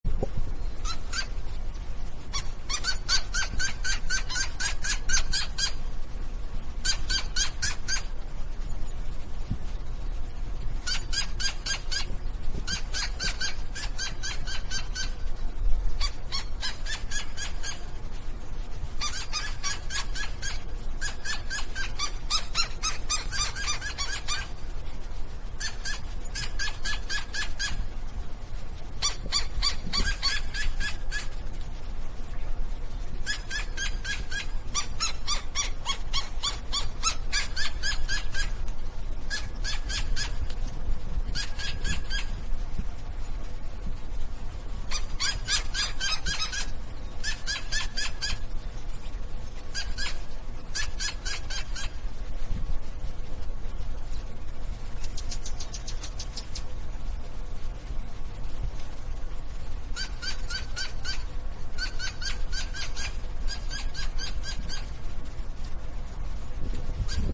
Birds Chirping And Squeaking Bouton sonore